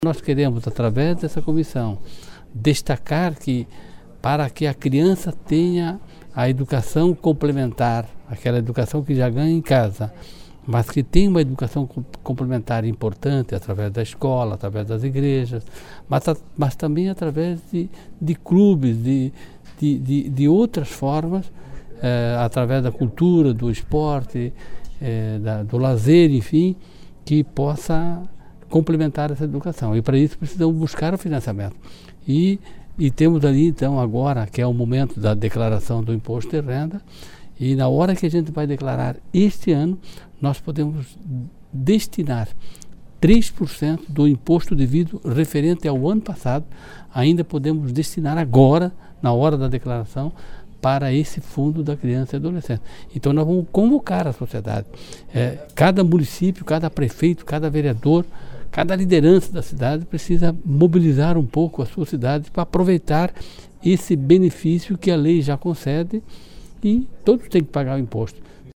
Ouça abaixo o que disseram os presidentes das demais comissões instaladas nesta quarta-feira:
Serafim Venzon (PSDB) - Comissão de Defesa dos Direitos da Criança e do Adolescente